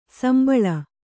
♪ sambaḷa